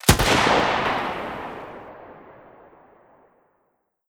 Sniper1_Shoot 01.wav